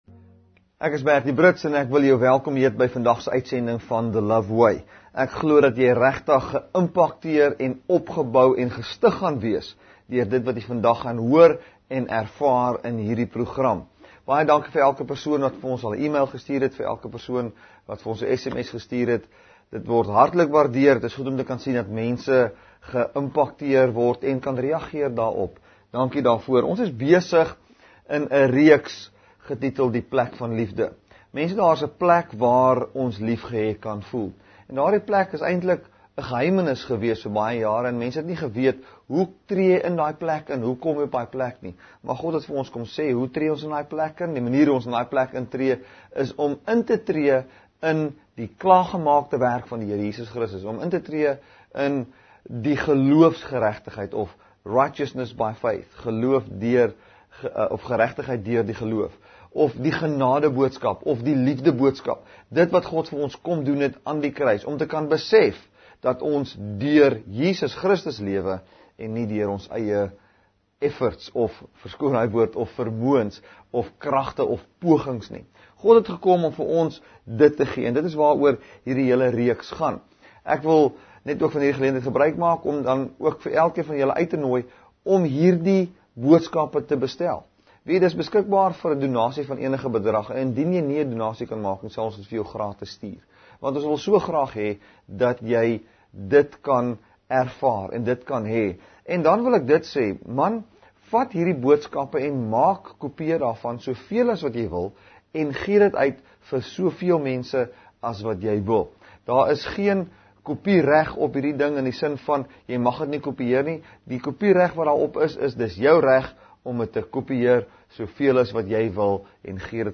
May 24, 2016 | TV BROADCASTING | Kruiskyk Uitsendings